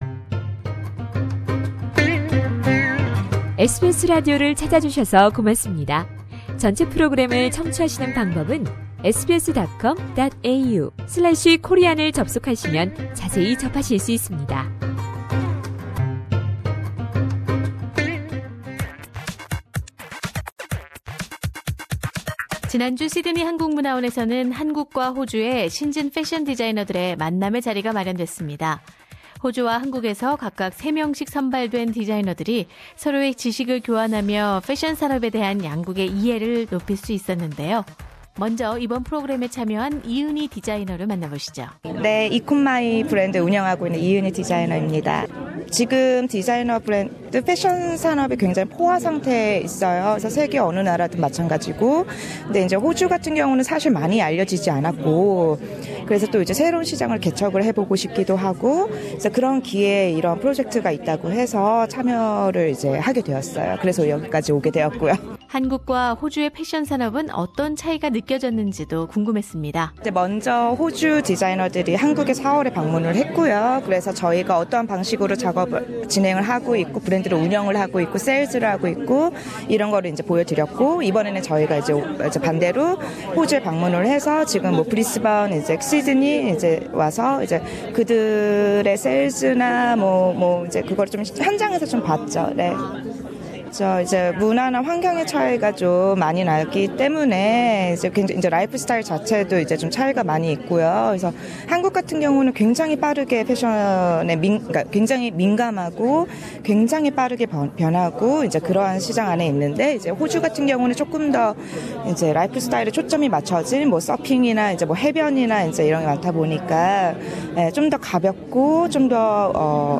[인터뷰] 호주를 방문한 신진 패션디자이너 3인을 만나다